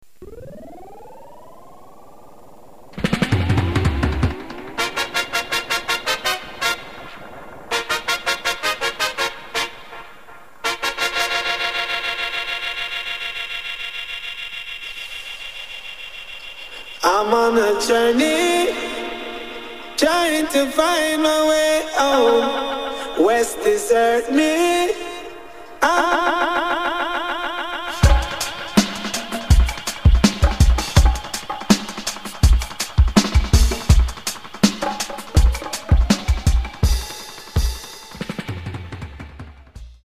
b: Dub
Genre: Reggae